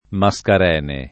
maSkar$ne] (antiq. Mascaregne [